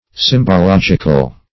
symbological.mp3